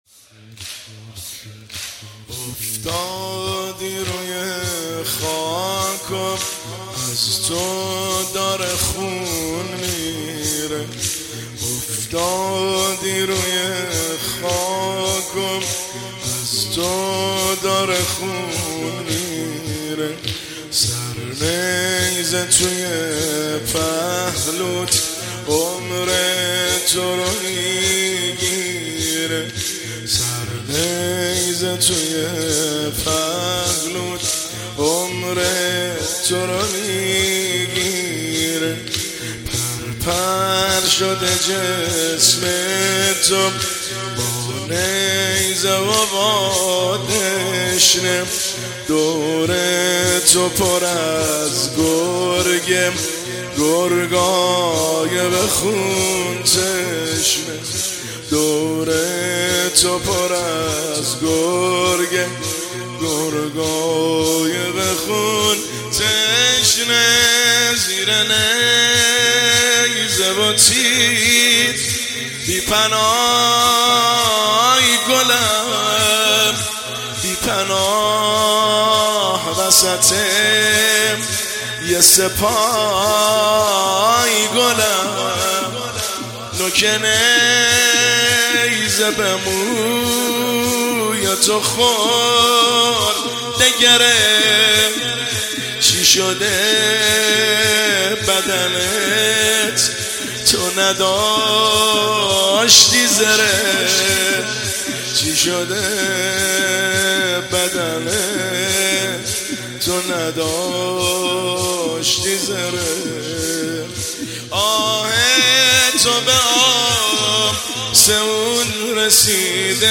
زمینه – شب ششم محرم الحرام 1404